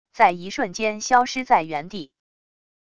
在一瞬间消失在原地wav音频生成系统WAV Audio Player